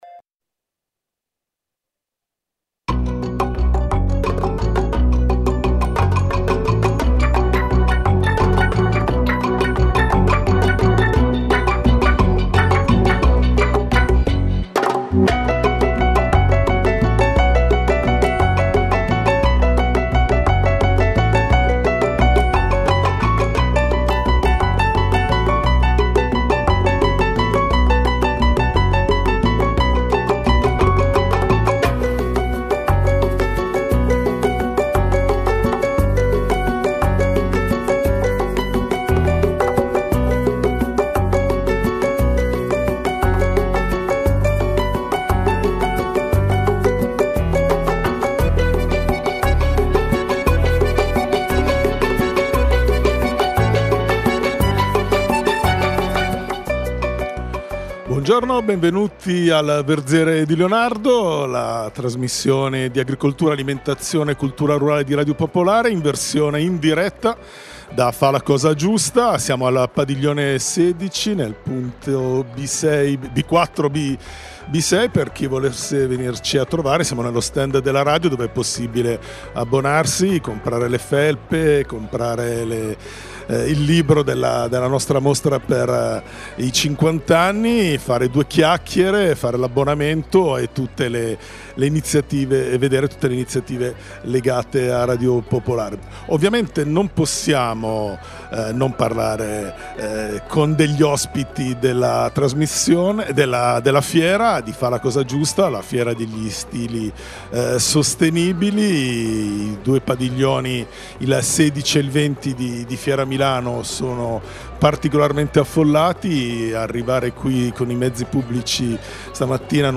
Puntata in diretta dal salone degli stili di vita sostenibile dai padiglioni di Fiera Milano a Rho Pero.